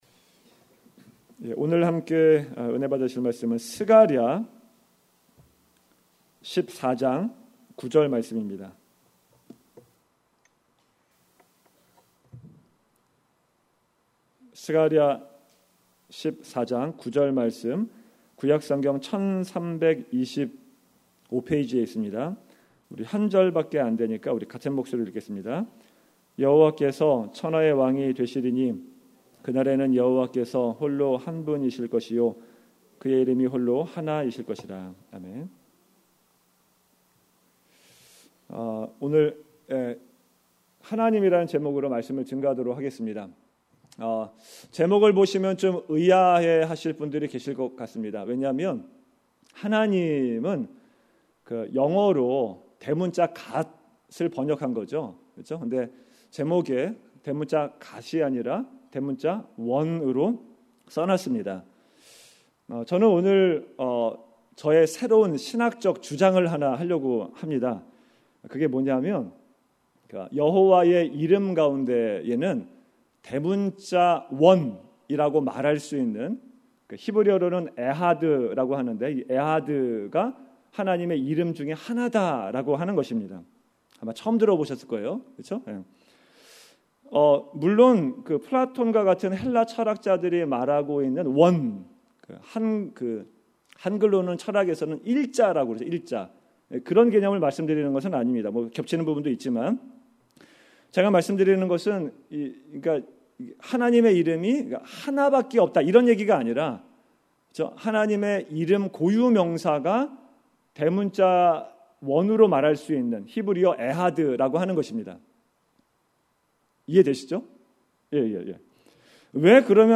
2018년 금요설교